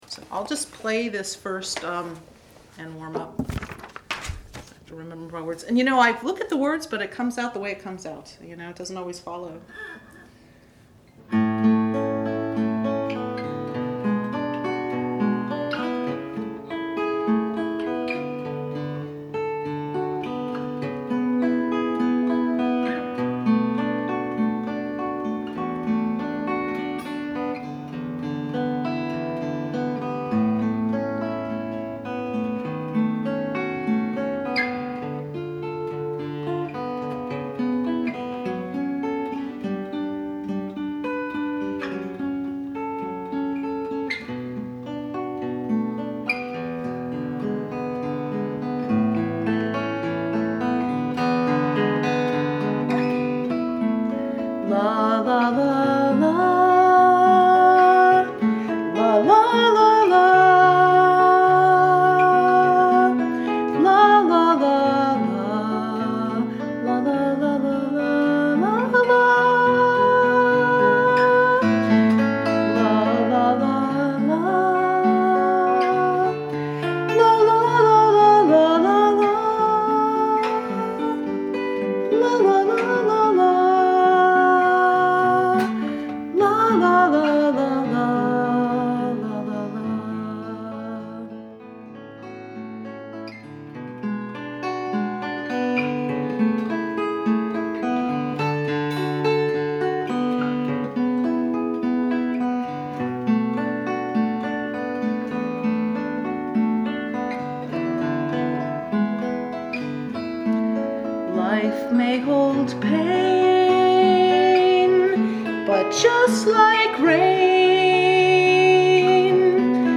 Just for fun, I recorded the first two verses while playing my guitar for a good friend.